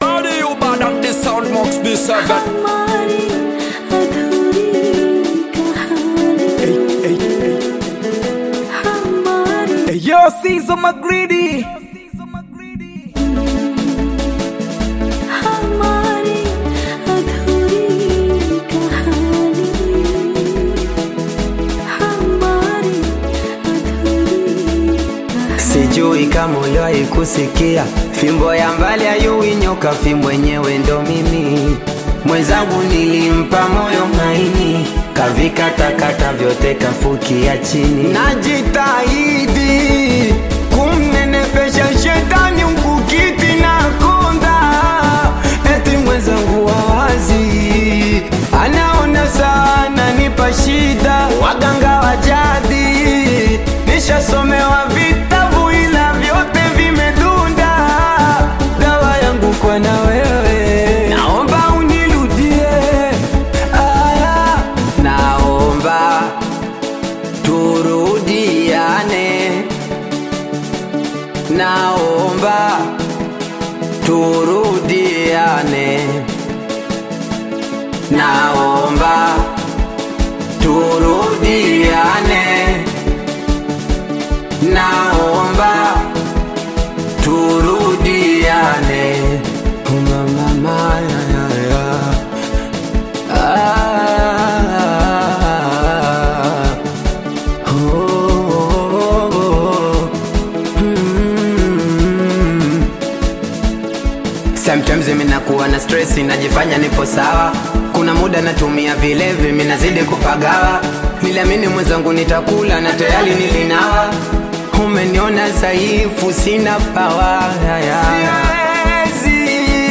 heartfelt Tanzanian Bongo Flava/Afro-Pop single
blends melodic hooks with Afro-inspired rhythms
Singeli